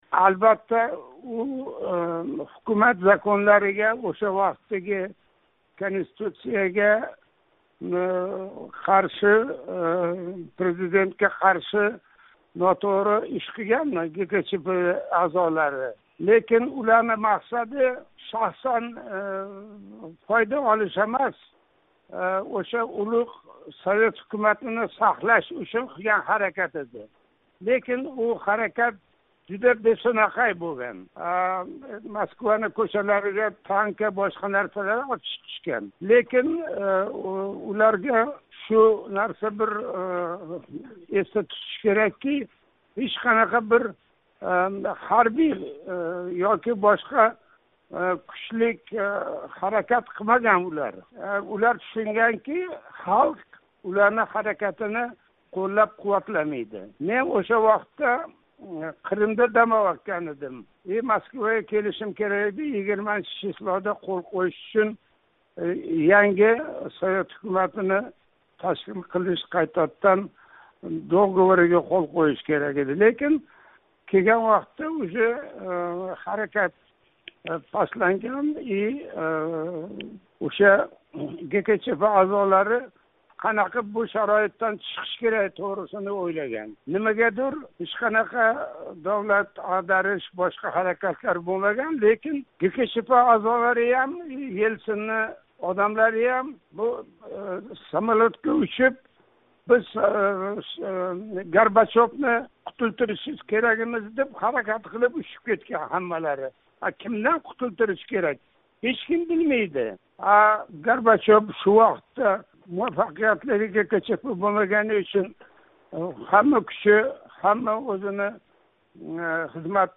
Рафиқ Нишонов билан суҳбат